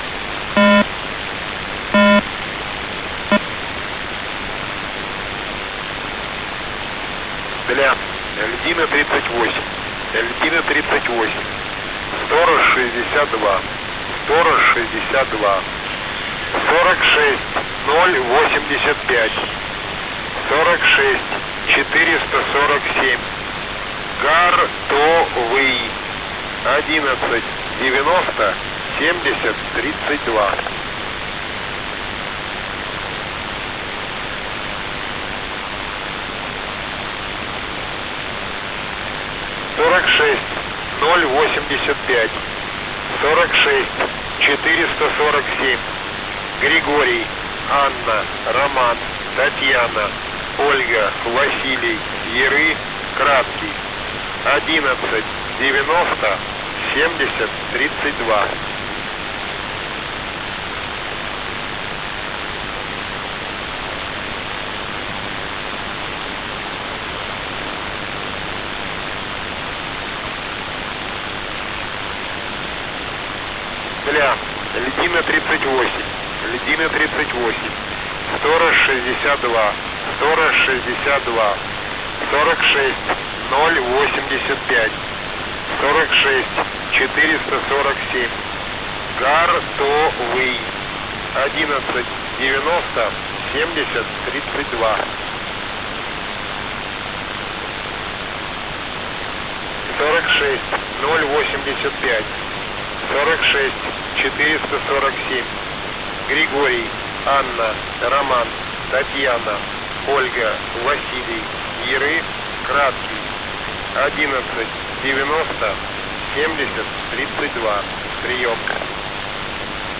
H3E (USB)